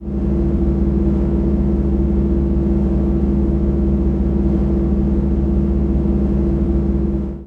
Index of /~quake2/baseq2/sound/cromavp2/ambients